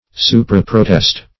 Search Result for " supraprotest" : The Collaborative International Dictionary of English v.0.48: Supraprotest \Su`pra*pro"test\, n. (Mercantile Law) An acceptance of a bill by a third person after protest for nonacceptance by the drawee.